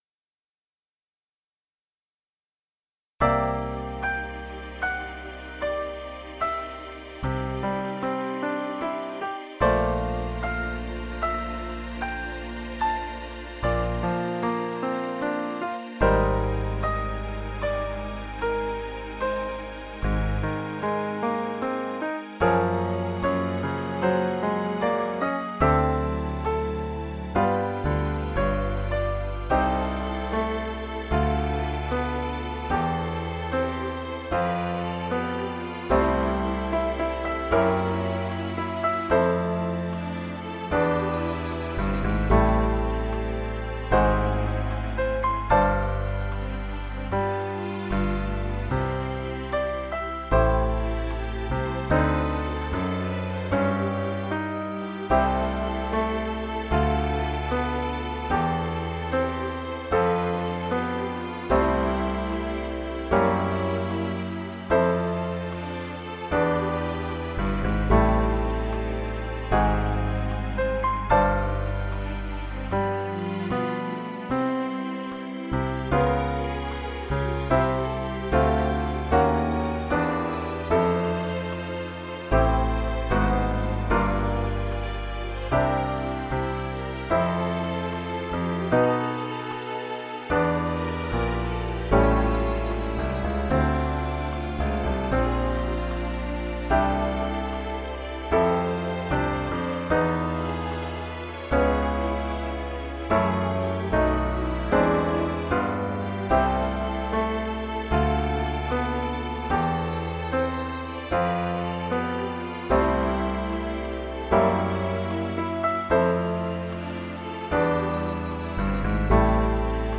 VA = Virtual Accompaniment